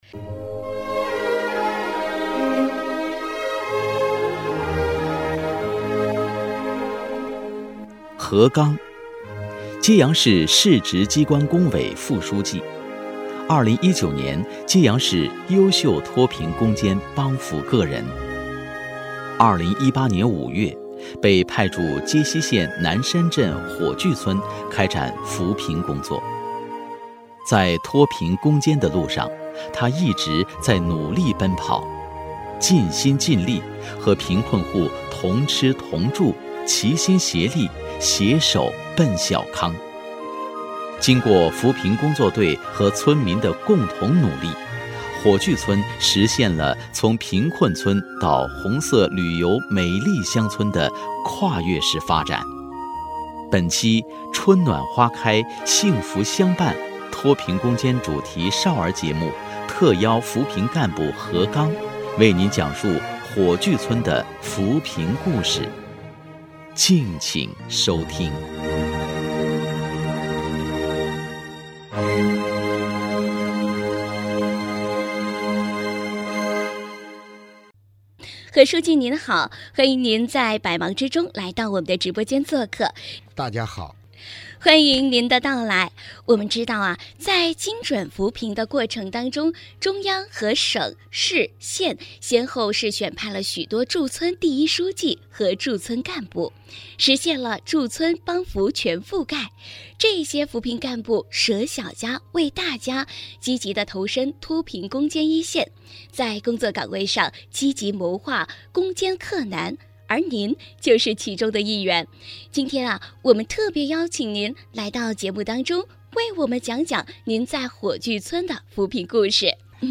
2020年7月份，揭阳电台特别推出《春暖花开幸福相伴》脱贫攻坚主题少儿节目，分享在脱贫攻坚奔小康过程中涌现出来的众多感人的故事。